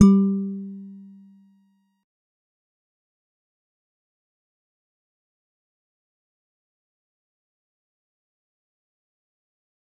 G_Musicbox-G3-mf.wav